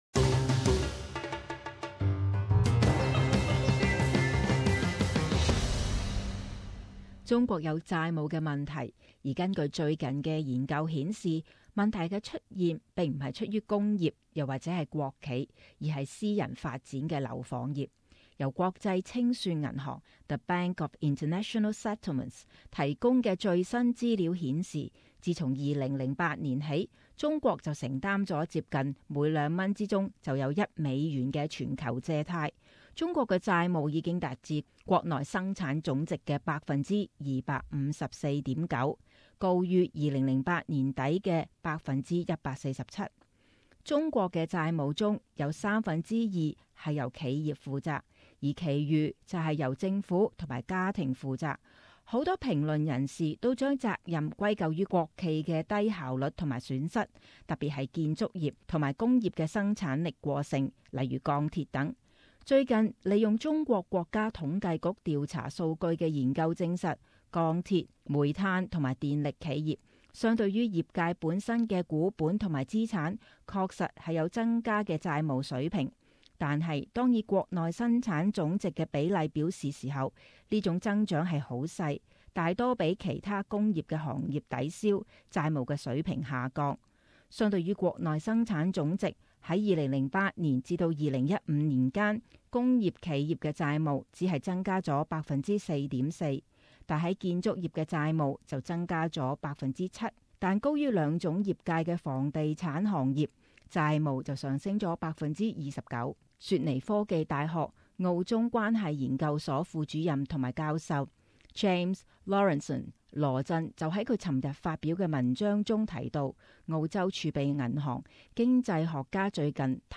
【时事报导】中国债务问题被私人房地产推动